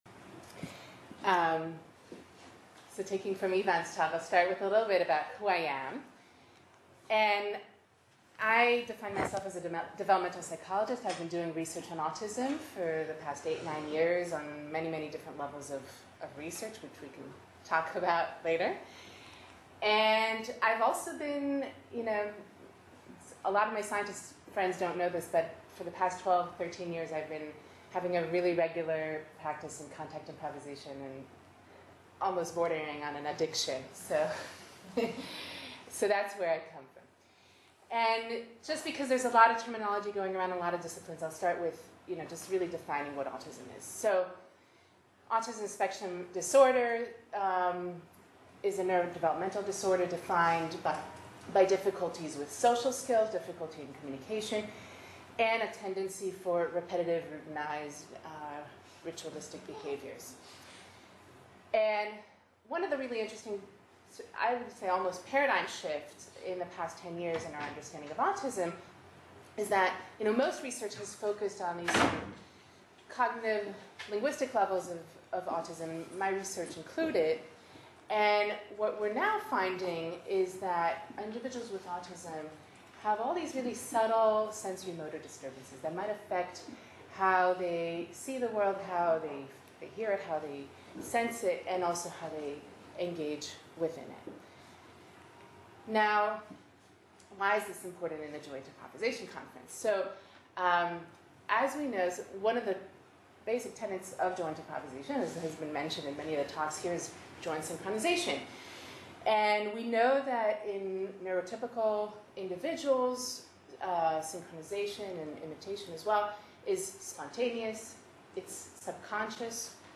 Short talk 2.3